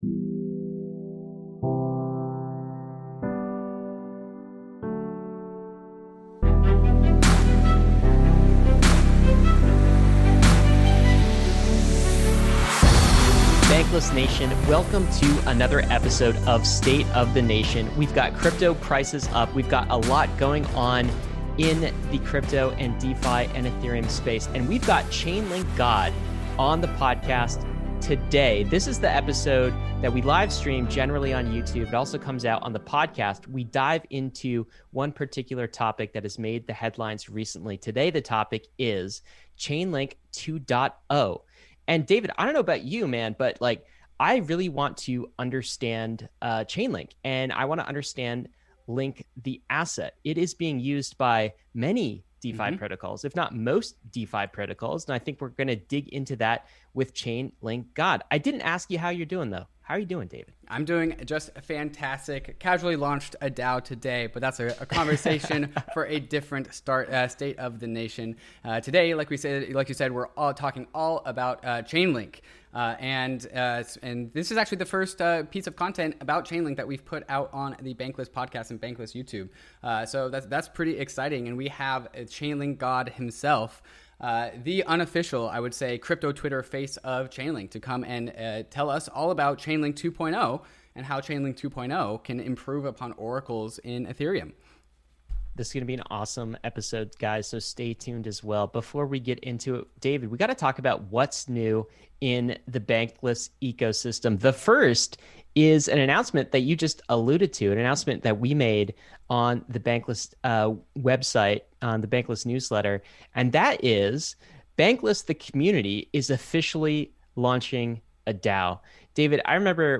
State of the Nation is live-streamed on Tuesdays at 11am PT.